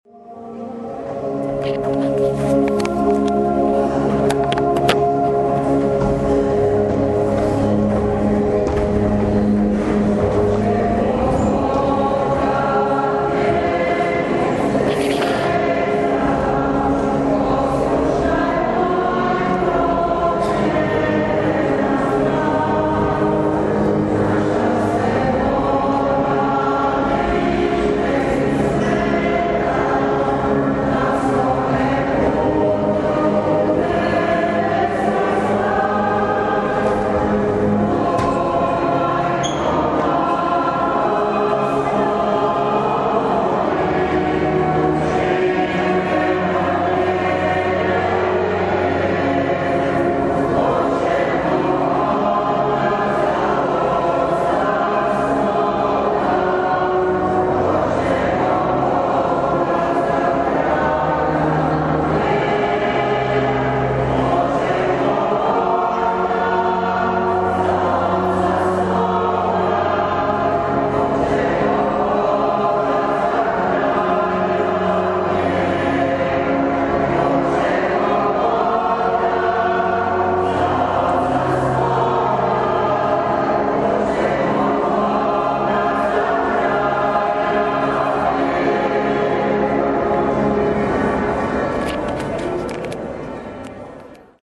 ŽUPNI ZBOR – AUDIO:
završna pjesma – ŽUPNI ZBOR